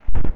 Added Tree Sound.
TreeHit.wav